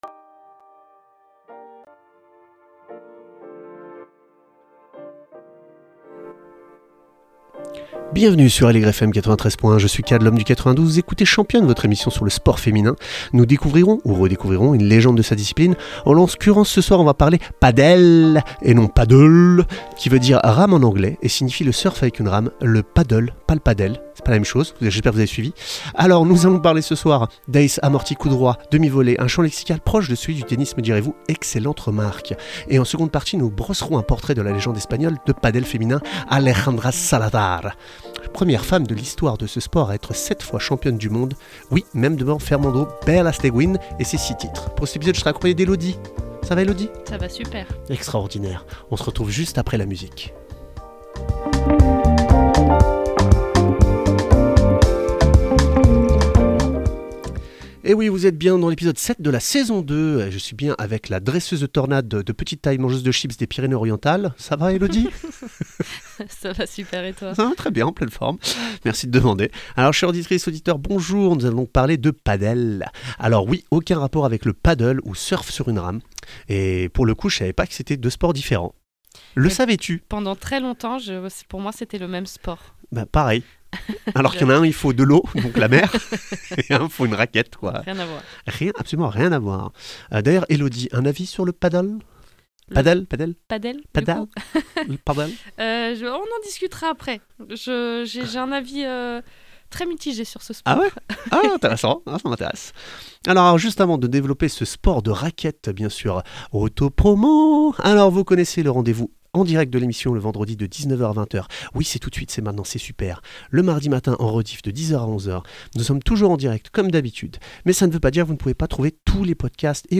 Qu’elles soient amatrices, semi-pros ou pros, nous échangeons avec des invitées inspirantes, qui partagent leurs visions et leurs expériences.